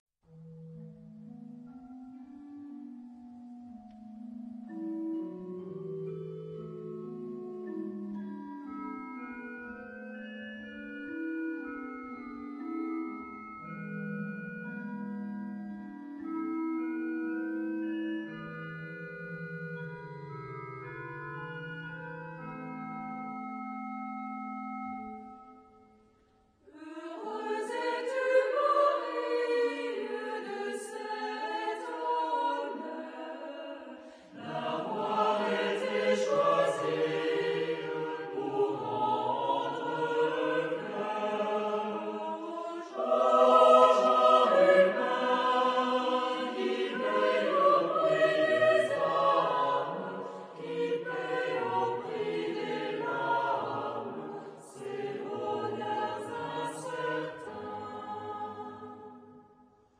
Genre-Stil-Form: Kirchenlied ; geistlich
Chorgattung: SAH  (3-stimmiger gemischter Chor )
Tonart(en): a-moll